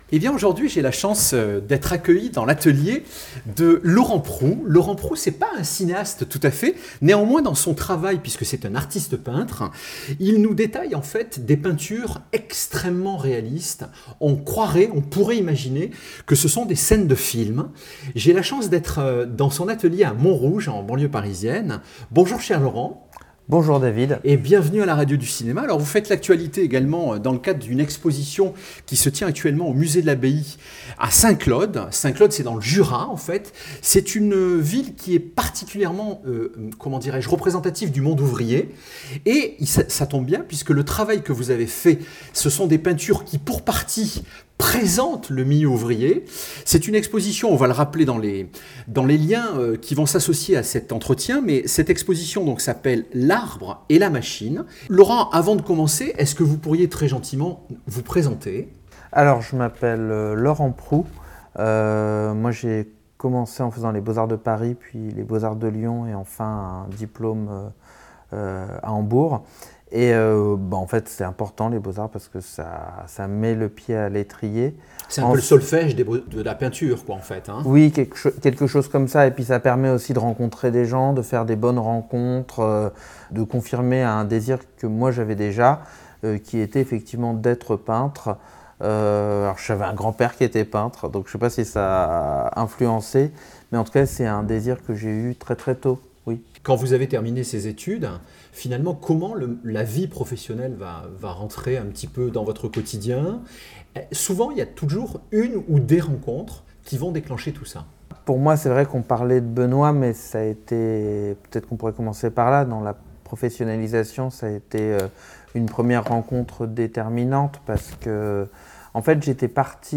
dans son atelier de Montrouge